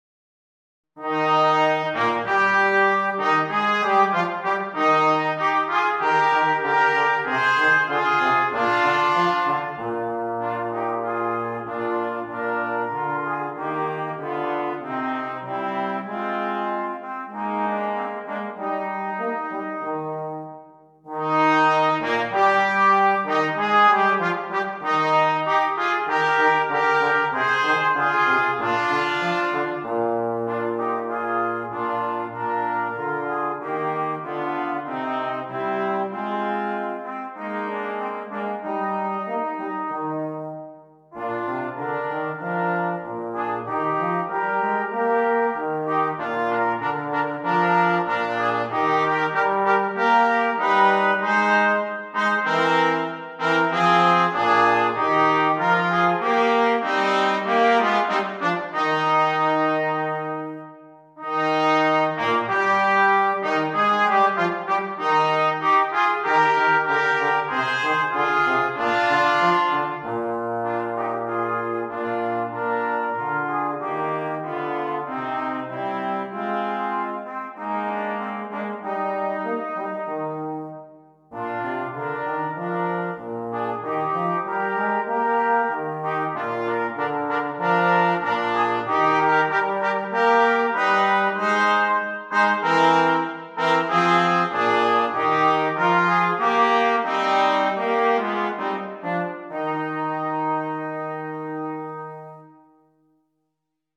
Gattung: für variables Bläsertrio - Holzbläser/Blechbläser
Besetzung: Ensemble gemischt
(variables Bläsertrio - Holzbläser/Blechbläser)